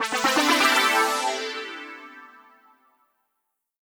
SYNTHPAD036_DISCO_125_A_SC3.wav